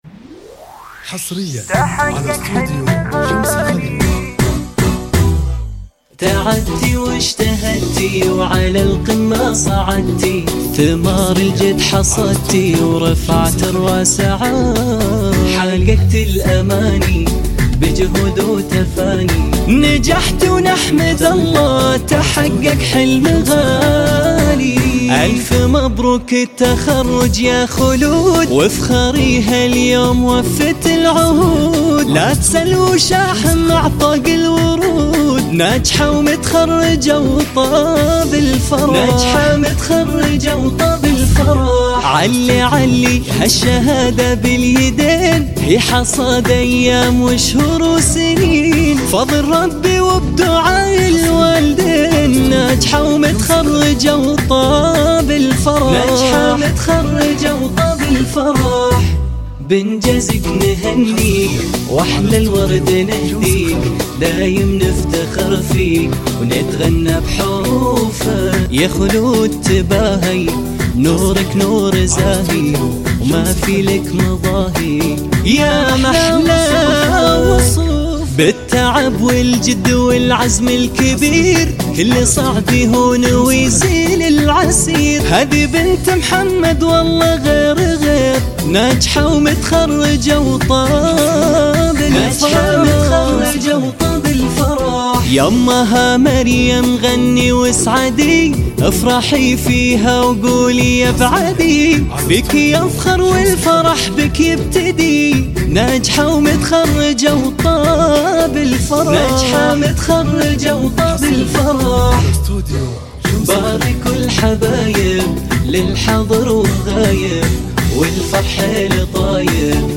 زفات تخرج
زفات موسيقى – زفات تخرج